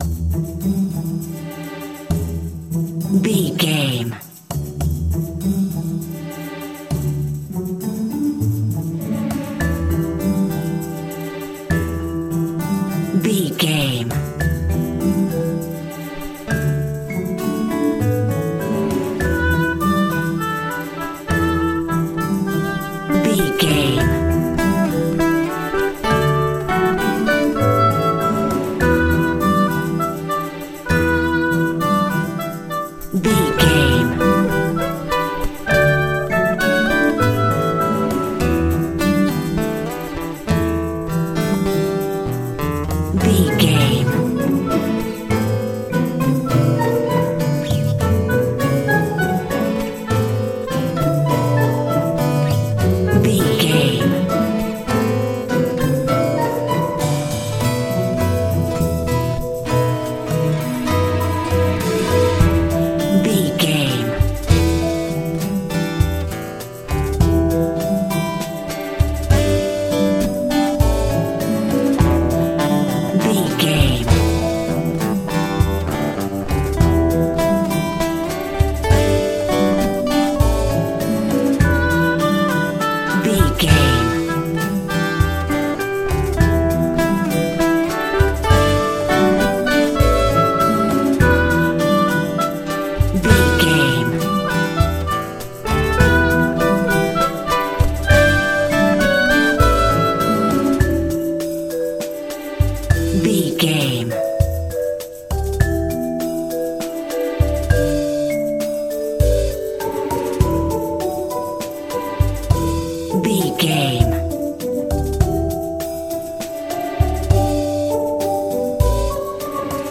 Aeolian/Minor
strings
bass guitar
acoustic guitar
flute
percussion
silly
goofy
comical
cheerful
perky
Light hearted
quirky